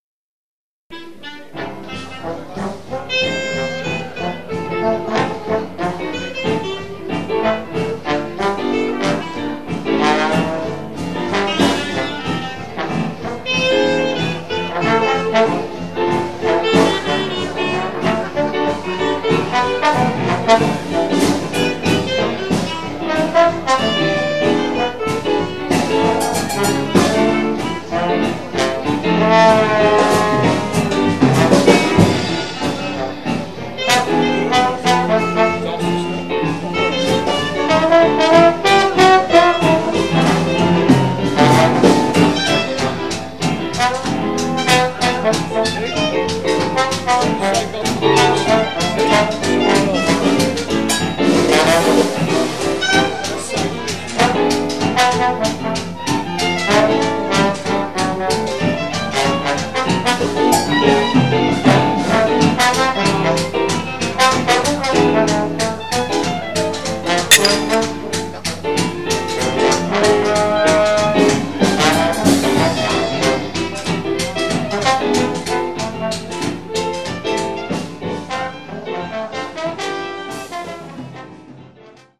Label: Private recording (recorded in the open air)